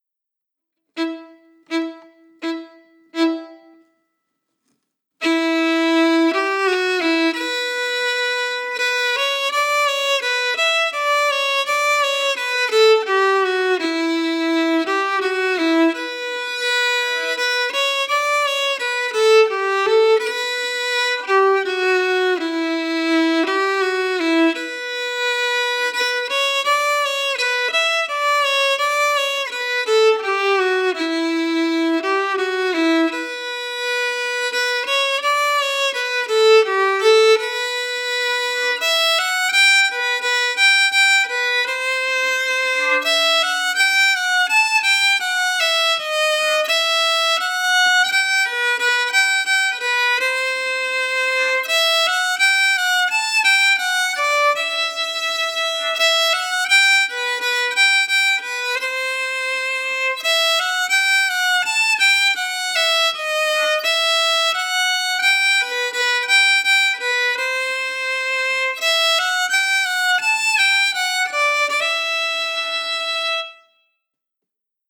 Key: Em
Form: Bourrée a  trois temps (in 3/8)
Played slowly for learning